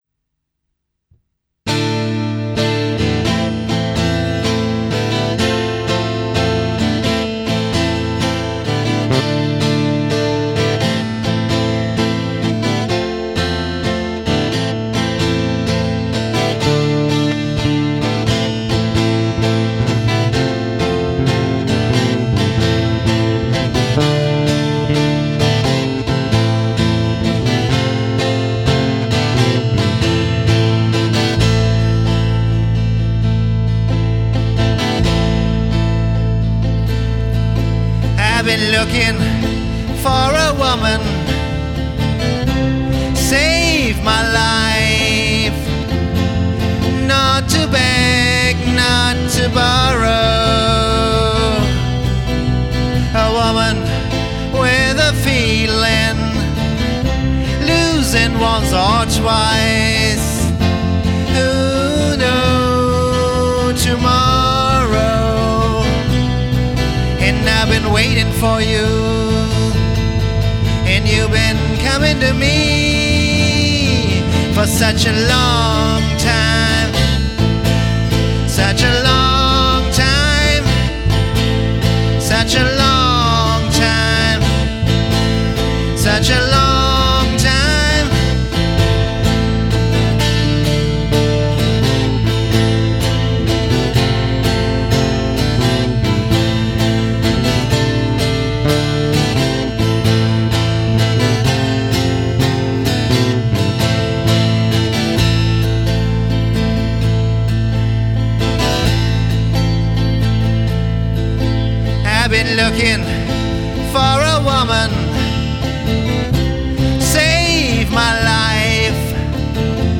===Warwick-Base===
- ...es geht um eine "WW Corvette-Proline" aus dem Jahr1992 (Serial-Nr.: M-000XXX-92)...
- ...der Bass ist alt (logisch) und "unverbastelt" - Original-PU und Elektronik...